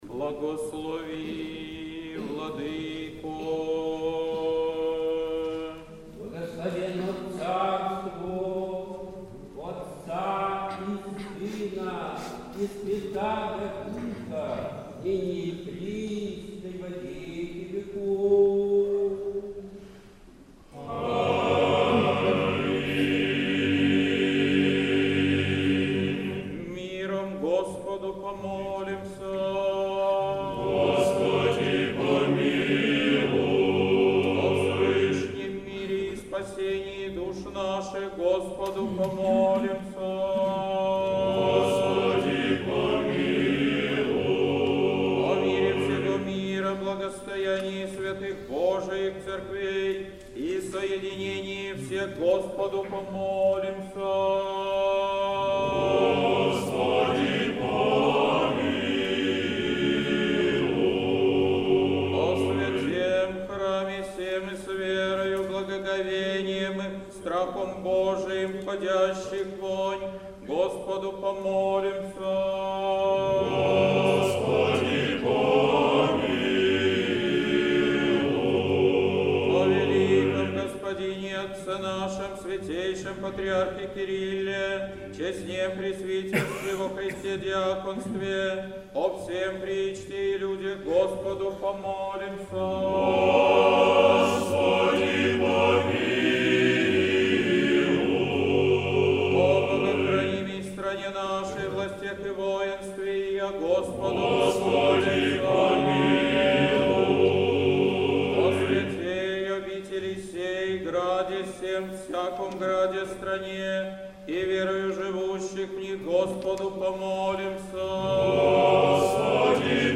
Божественная литургия. Хор Сретенского монастыря.
Божественная литургия в Сретенском монастыре в Неделю 2-ю Великого поста